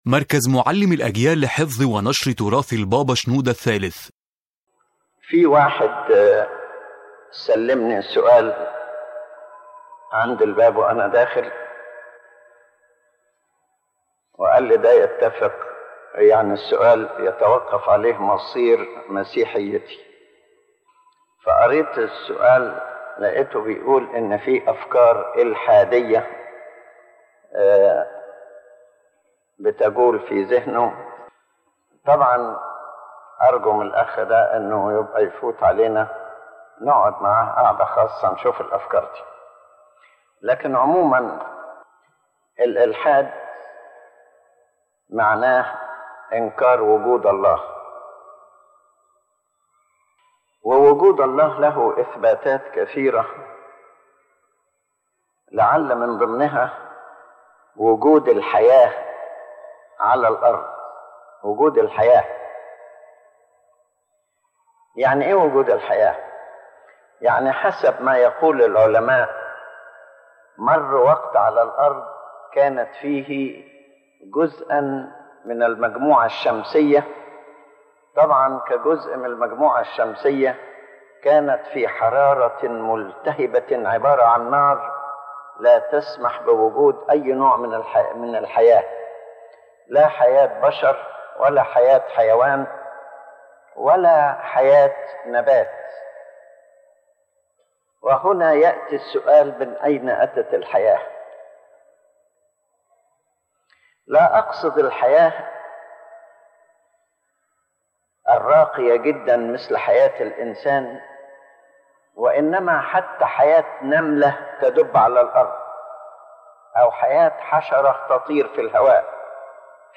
The General Idea of the Lecture